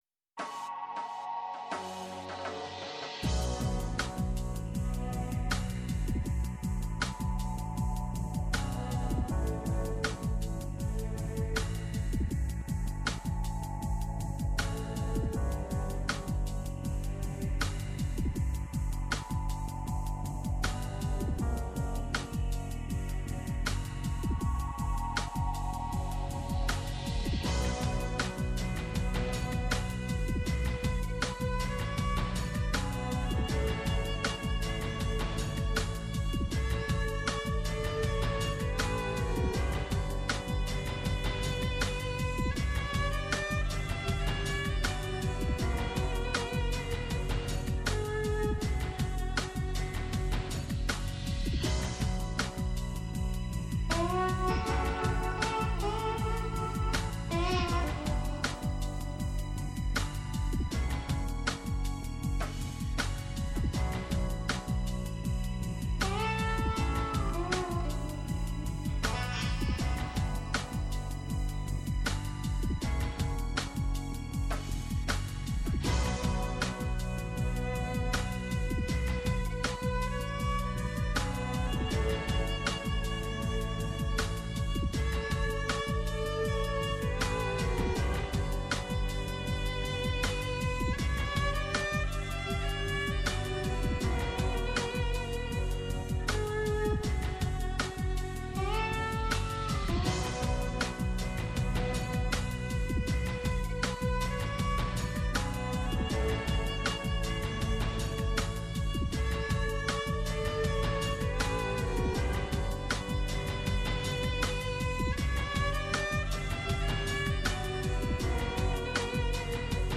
στο Πρώτο Πρόγραμμα της Ελληνικής Ραδιοφωνίας.